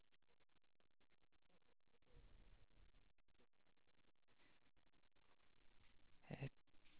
osa 0918 (Monaural AU Sound Data)